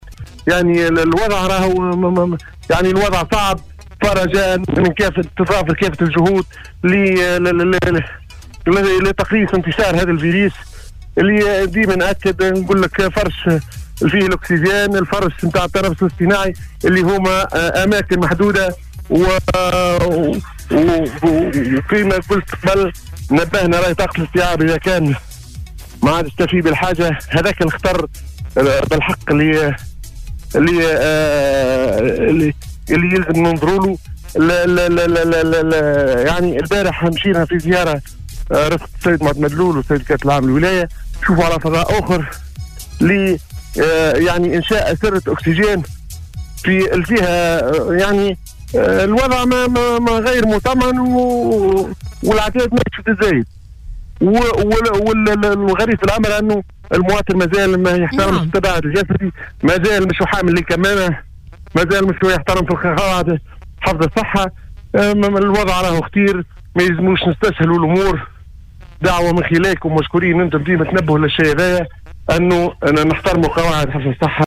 اكد المدير الجهوي للصحة بالقصرين عبد الغني الشعابني صباح اليوم في مداخلته ببرنامج بونجور ويكاند على موجة السليوم اف ام ان الوضع الحالي بالجهة يعتبر عالي الخطورة داعيا اهالي القصرين الى مزيد توخي الحذر و الحيطة.